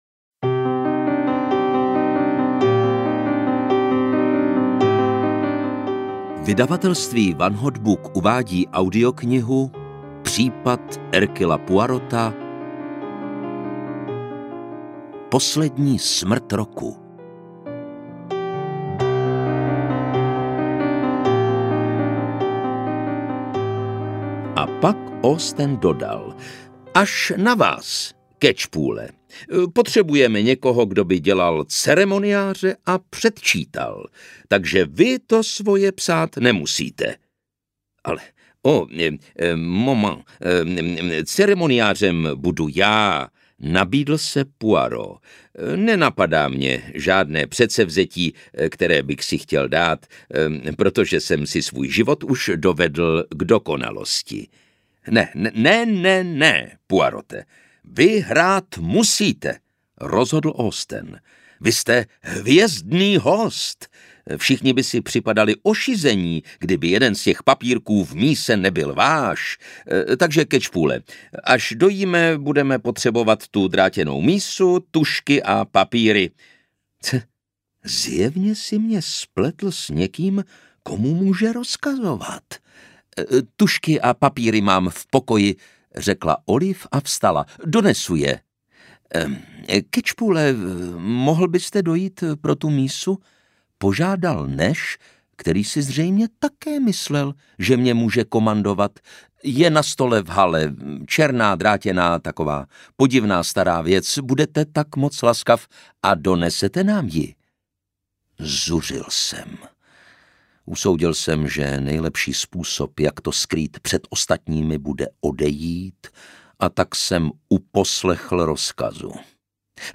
Poslední smrt roku audiokniha
Ukázka z knihy